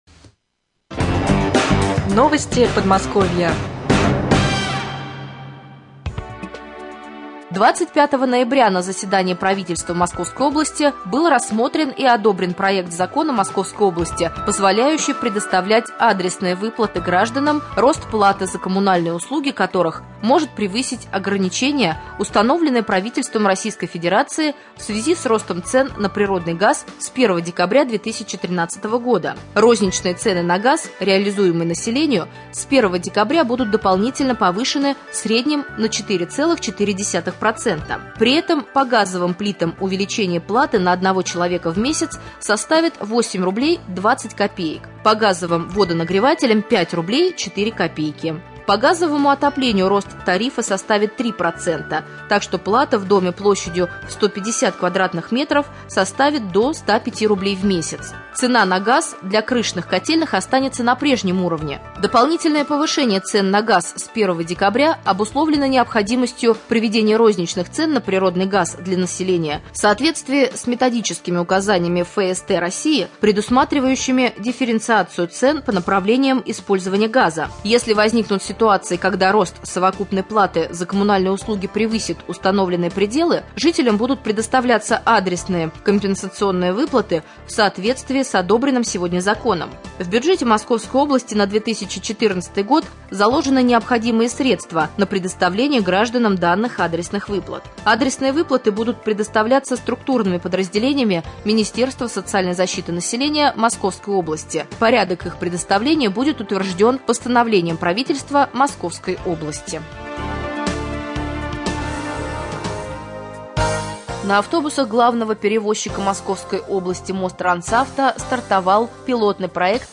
2.Новости